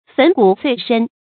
粉骨碎身 fěn gǔ suì shēn 成语解释 指身躯粉碎，牺牲生命。
成语简拼 fgss 成语注音 ㄈㄣˇ ㄍㄨˇ ㄙㄨㄟˋ ㄕㄣ 常用程度 一般成语 感情色彩 中性成语 成语用法 作谓语、定语、状语、宾语；用于人 成语结构 联合式成语 产生年代 古代成语 近 义 词 粉身碎骨 、 粉身灰骨 成语例子 〖示例〗俺不免乘此，到那厮跟前，痛骂一场，出了这口愤气。